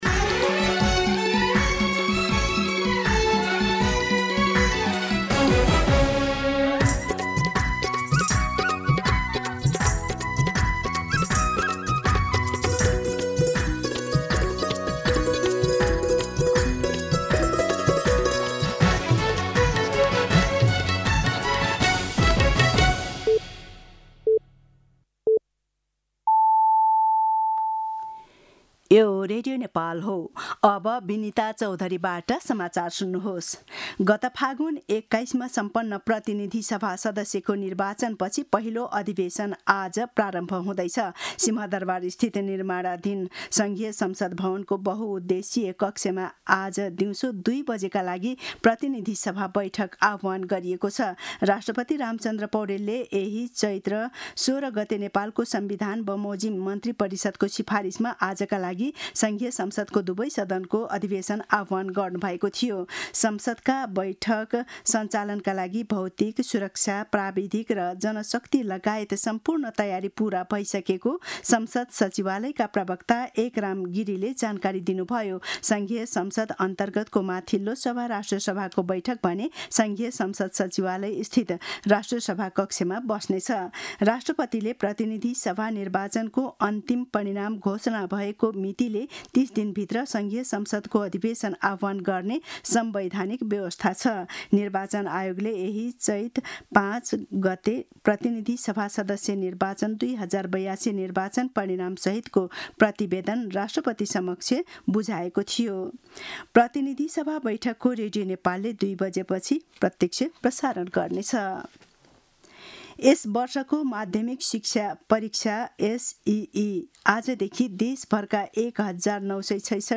दिउँसो १ बजेको नेपाली समाचार : १९ चैत , २०८२